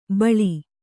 ♪ baḷi